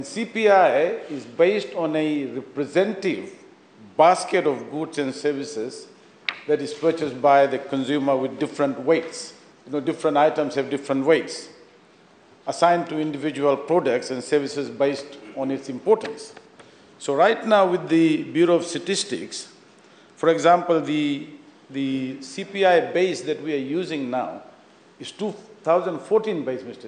This has been highlighted by Finance Minister Professor Biman Prasad in Parliament yesterday.